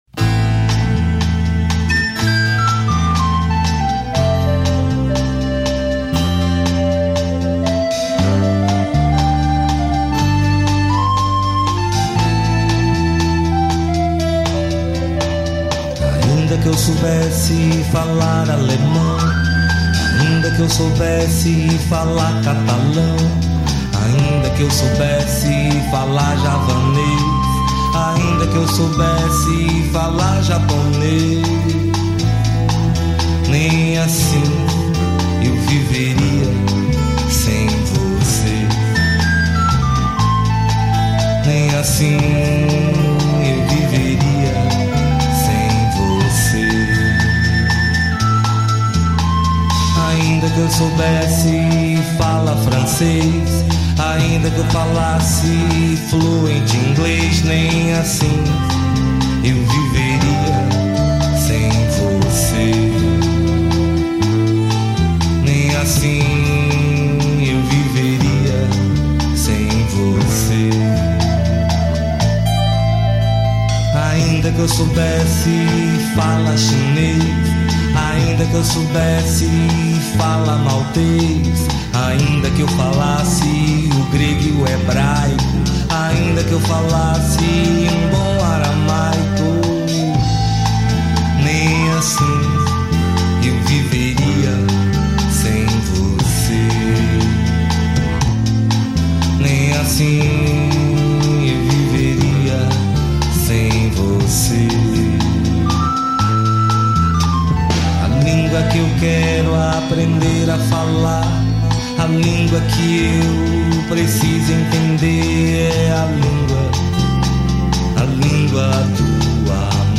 2156   03:27:00   Faixa:     Reggae